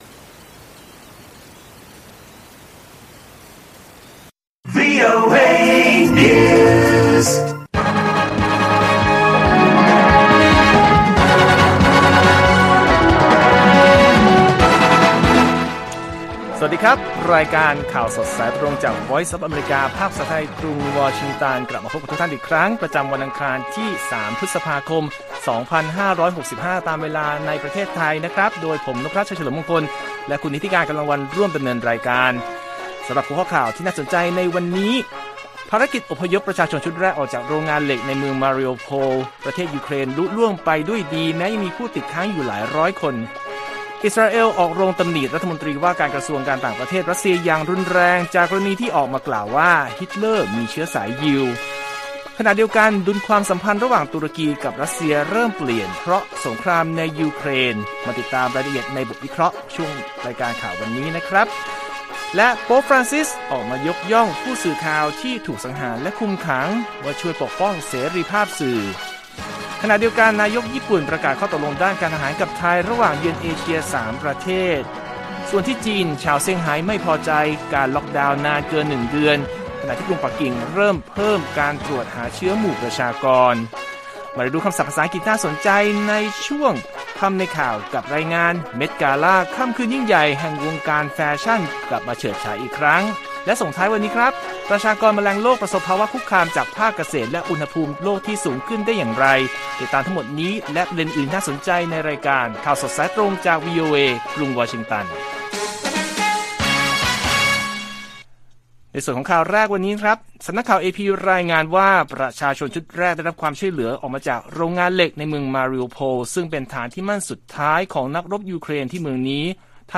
ข่าวสดสายตรงจากวีโอเอไทย 8:30–9:00 น.วันที่ 3 พ.ค.65